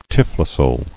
[typh·lo·sole]